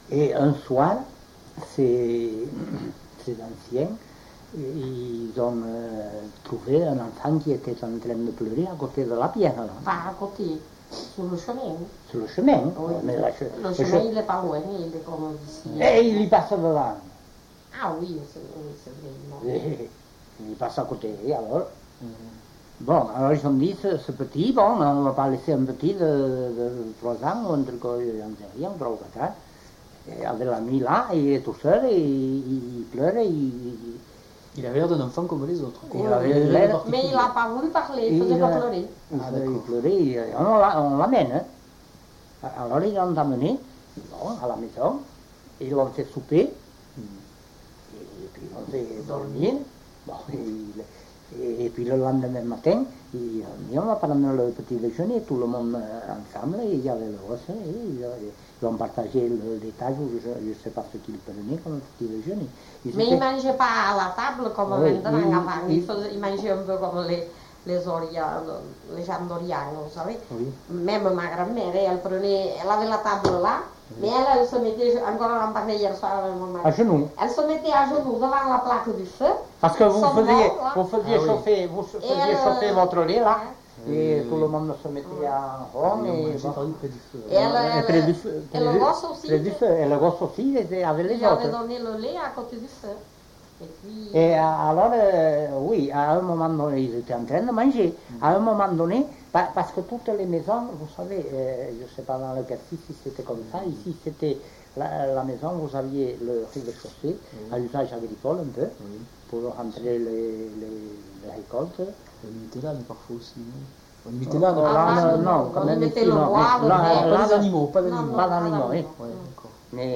Aire culturelle : Couserans
Genre : conte-légende-récit
Effectif : 1
Type de voix : voix d'homme
Production du son : parlé